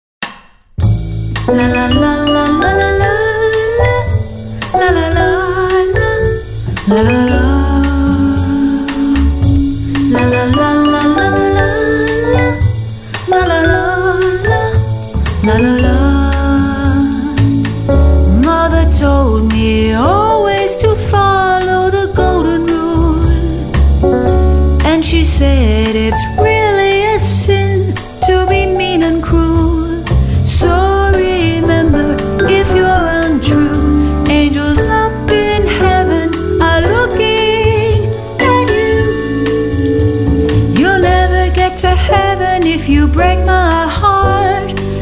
vocal&bass
vibraphone
piano
Recorded at Avatar Studio in New York on March 23 & 24, 2011